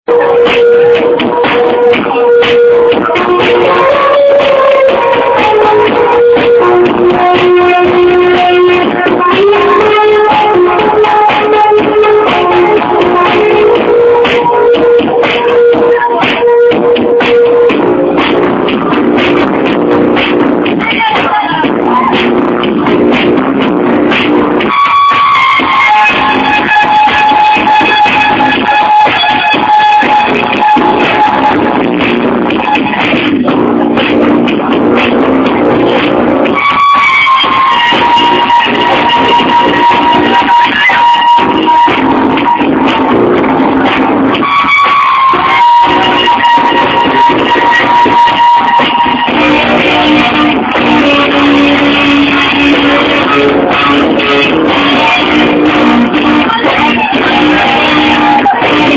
it's like electro-trance or sth and it's very nice. heard in club some time ago. your help will be appreciated!